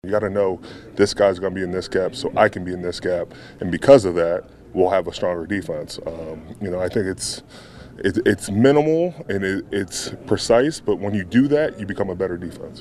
Defensive captain Cam Heyward says he and his teammates need to know that each guy is playing his assignment on every play.